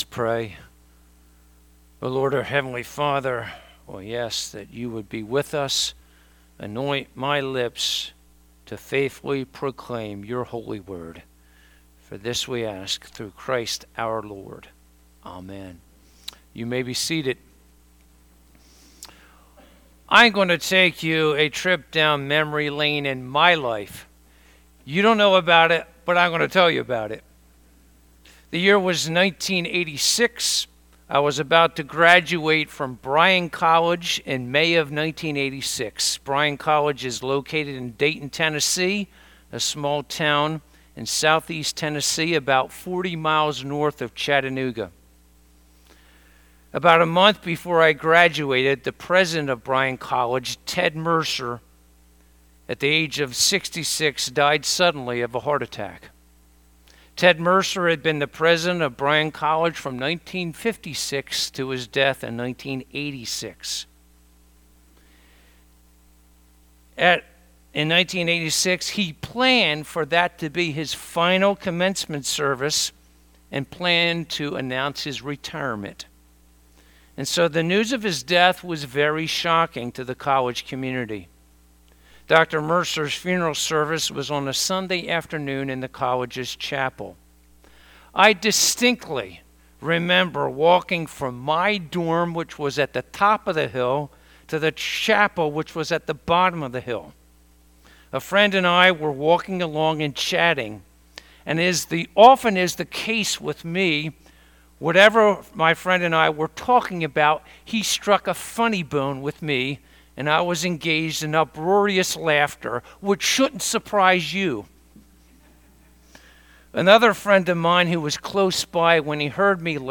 Bible Text: Hebrews 12:4-11 | Preacher